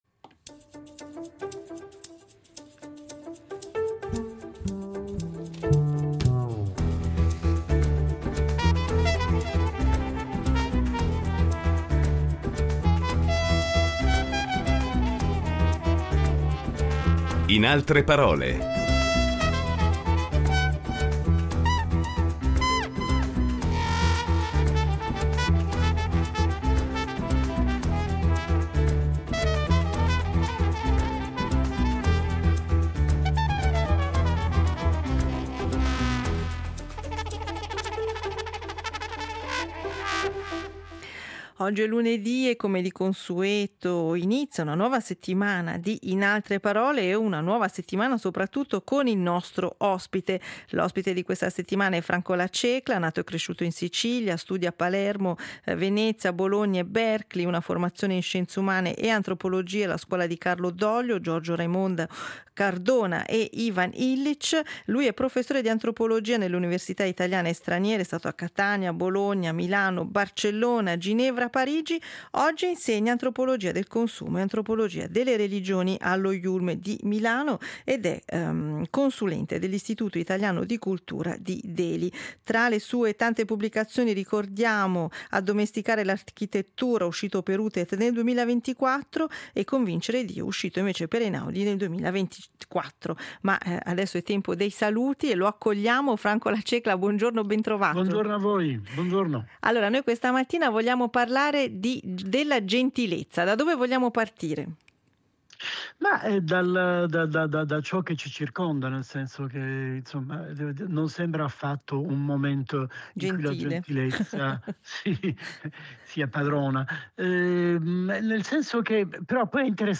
Incontro con l’antropologo culturale e scrittore